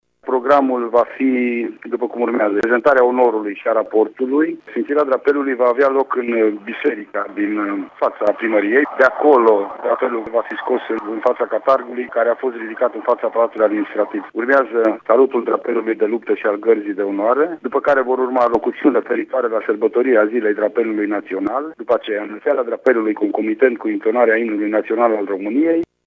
Prefectul de Mureș, Lucian Goga: